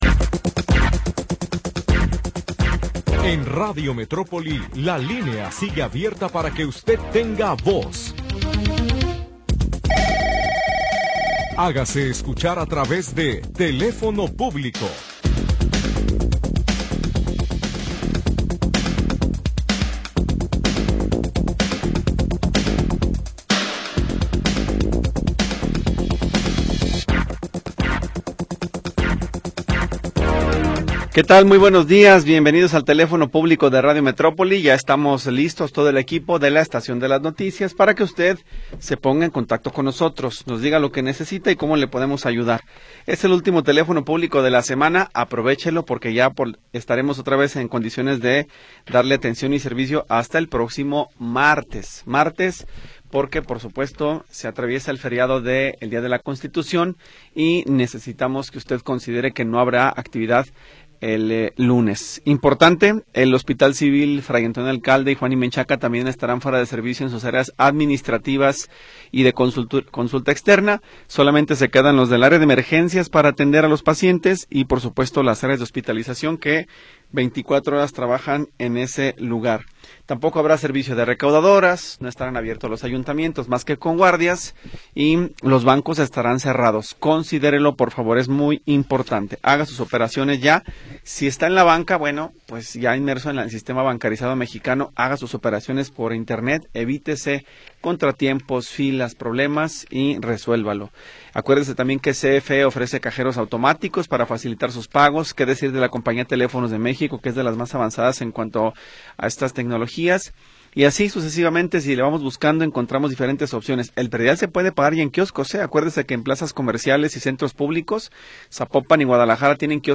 Programa transmitido el 30 de Enero de 2026.